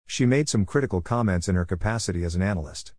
確認テスト(ディクテーション)
以下の例文で弱化した単語を聴き取る練習をしましょう。